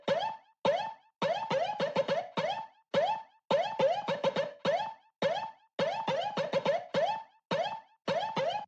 Avant Pop Vocals & Sounds by 91Vocals
91V_AP_105_vocal_perc_loop_ooo_pitched_percussive